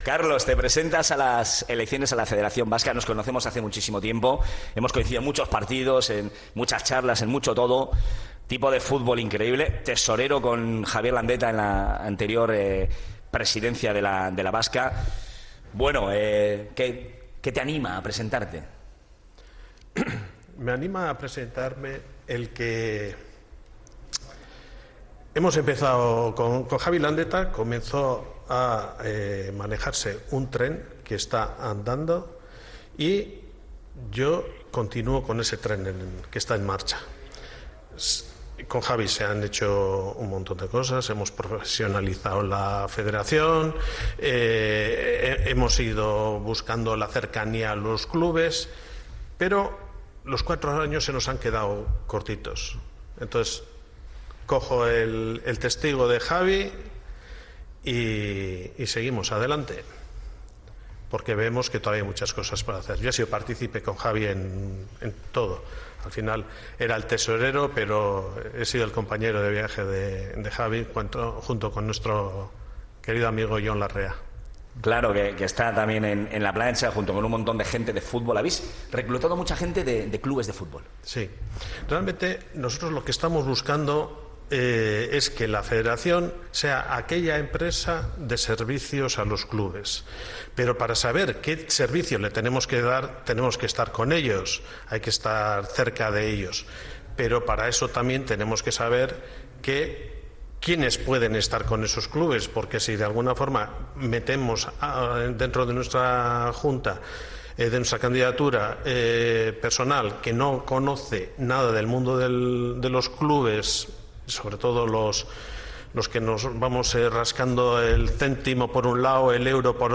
Onda Vasca Bizkaia en directo